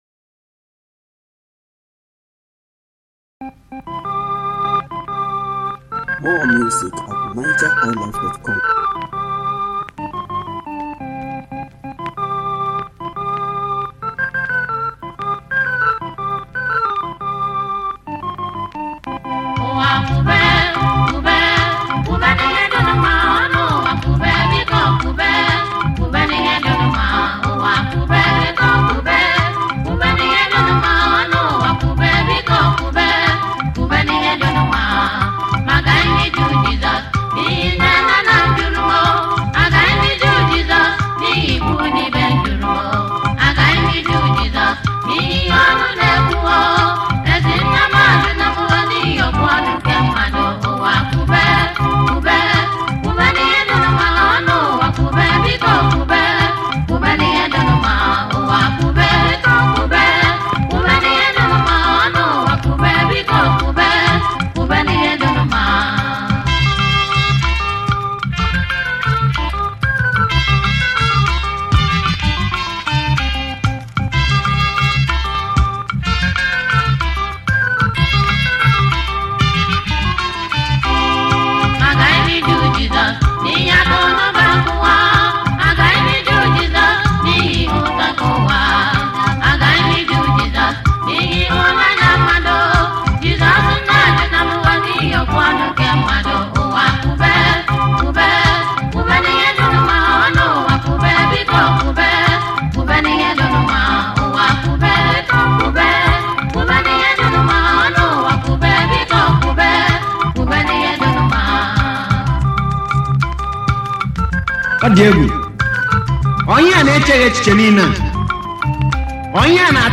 Home » Gospel